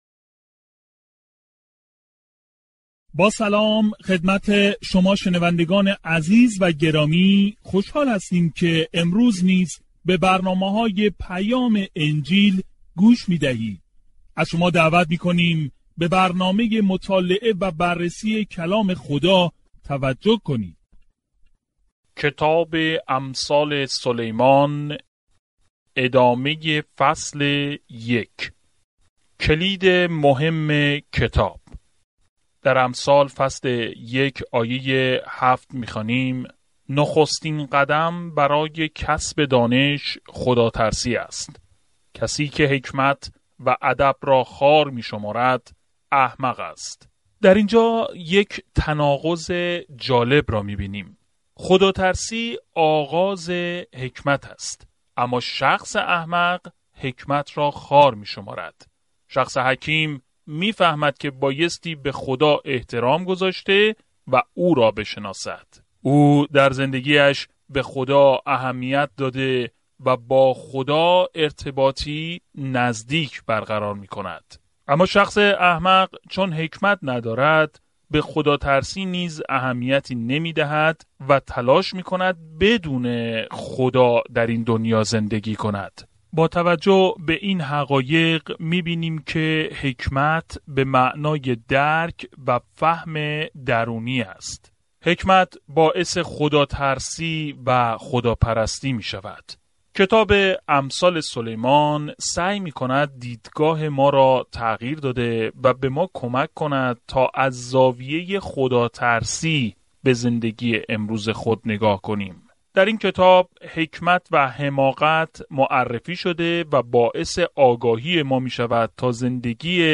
Scripture Proverbs 1:7-33 Proverbs 2:1-2 Day 2 Start this Plan Day 4 About this Plan ضرب المثل ها جملات کوتاهی هستند که از تجربیات طولانی استخراج شده اند و حقیقت را به روشی می آموزند که به خاطر سپردن آسان باشد - حقایقی که به ما کمک می کنند تصمیمات عاقلانه ای بگیریم. همزمان با گوش دادن به مطالعه صوتی و خواندن آیات منتخب از کلام خدا، روزانه در ضرب المثل ها سفر کنید.